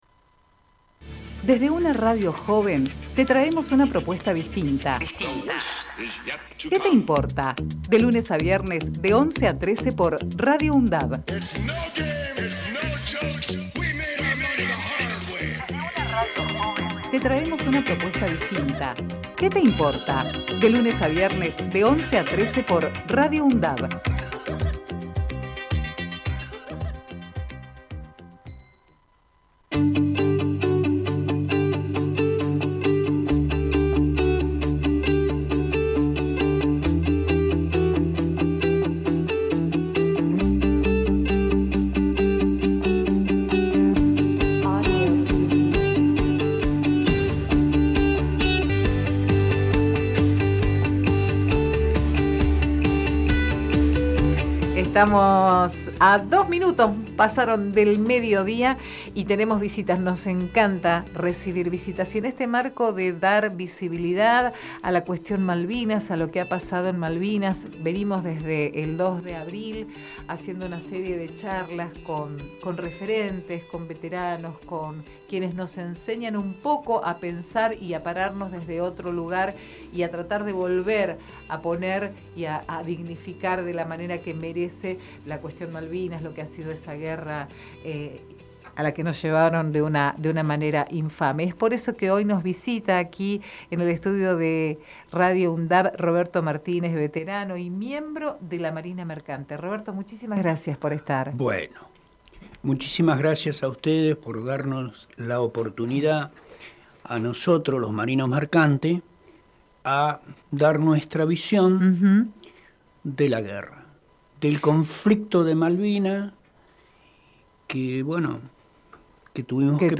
COLUMNA MALVINAS ENTREVISTA